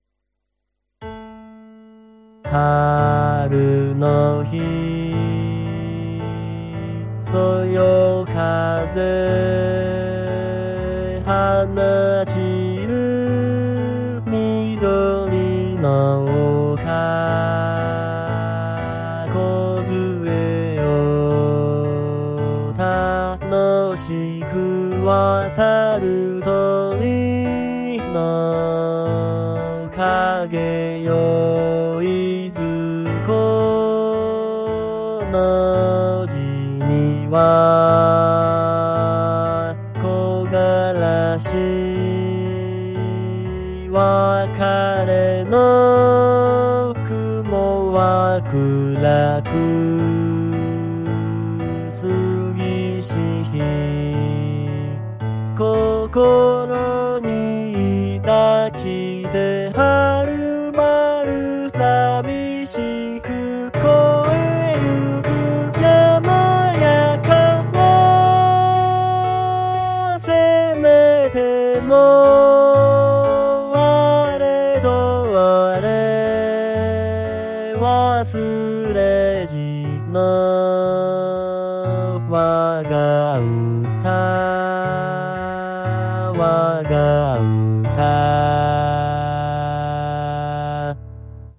音声は、途中の難しいところは入っておりません。